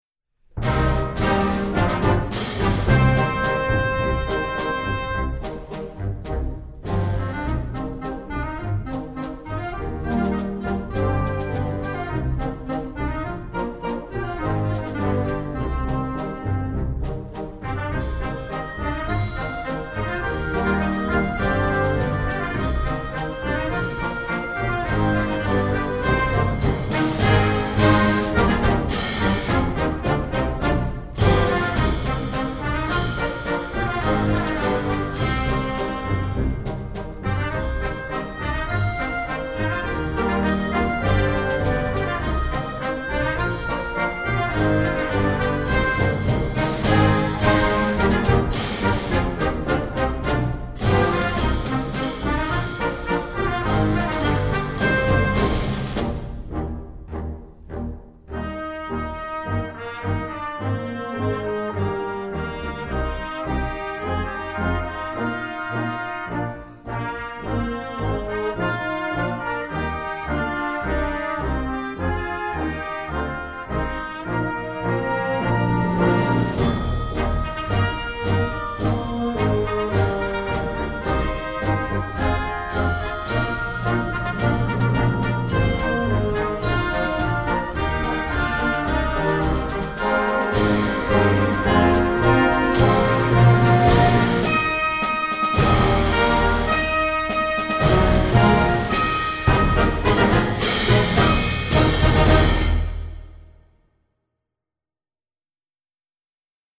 Gattung: Marsch für Jugendblasorchester
Besetzung: Blasorchester